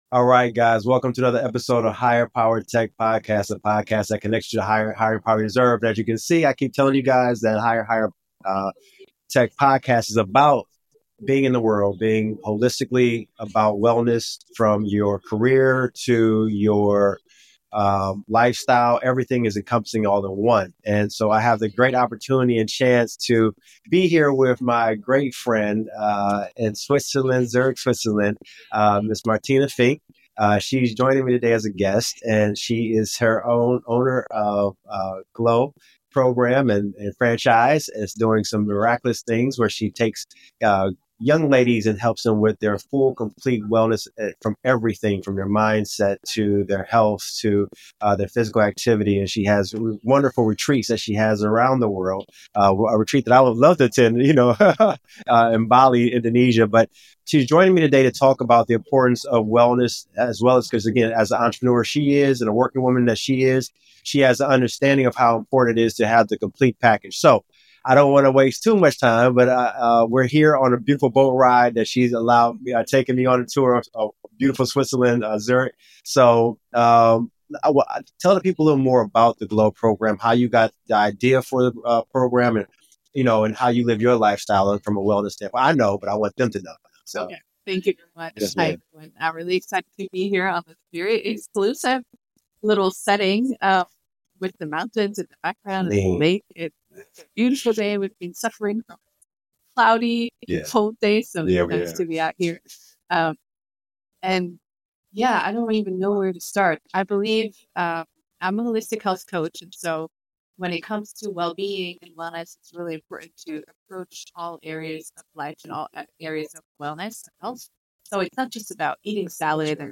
Broadcasting from a boat in Zurich, Switzerland, the two explore how a holistic approach to life and career can lead to sustainable success.